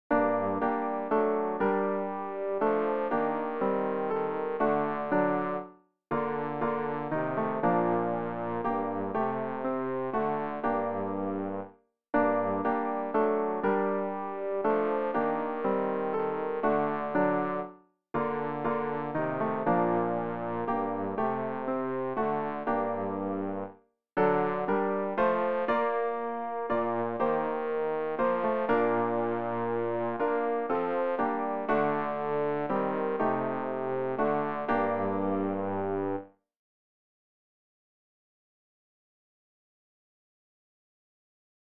rg-681-wer-nur-den-lieben-gott-laesst-walten-bass.mp3